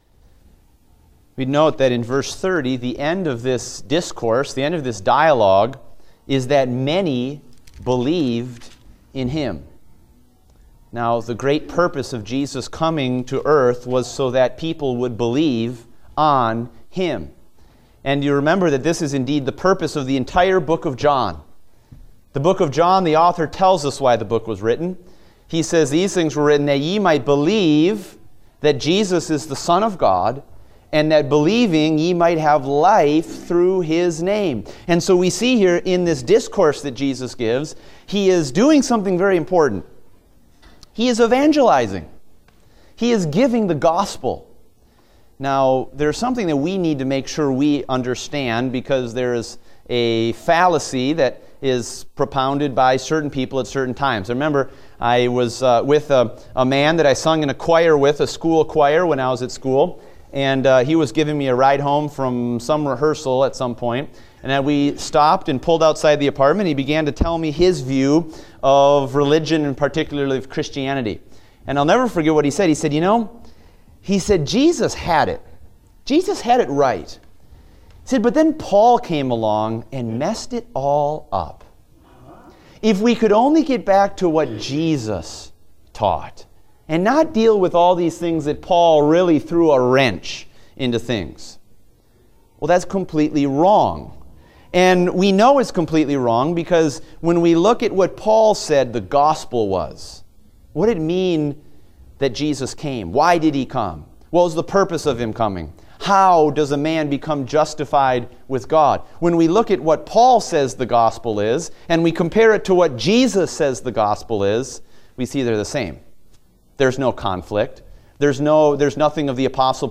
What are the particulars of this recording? Date: October 16, 2016 (Adult Sunday School)